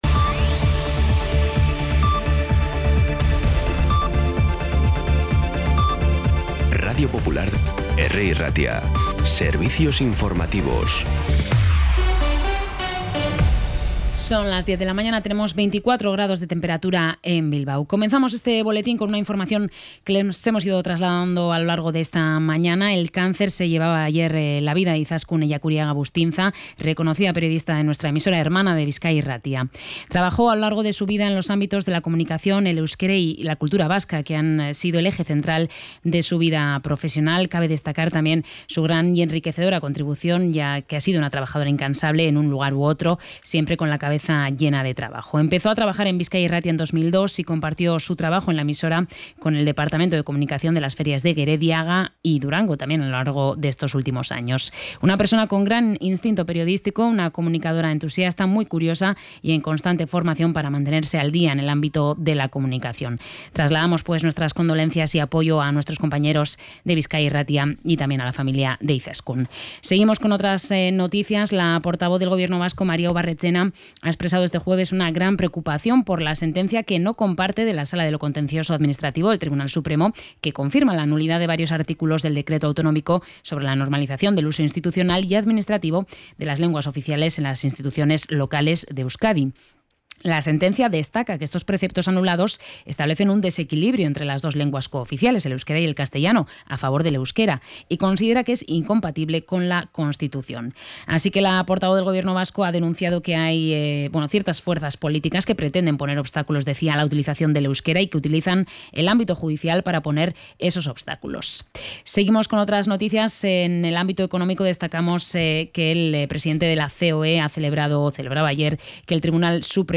Información y actualidad desde las 10 h de la mañana